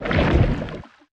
Sfx_creature_spikeytrap_pulling_03.ogg